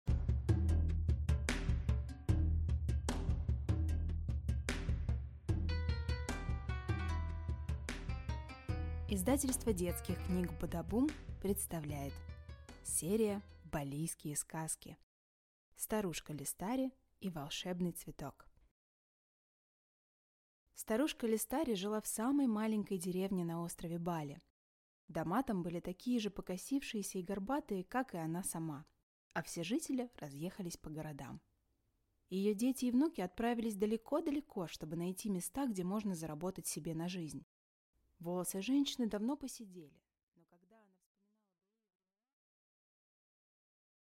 Аудиокнига Старушка Лестари и волшебный цветок | Библиотека аудиокниг
Прослушать и бесплатно скачать фрагмент аудиокниги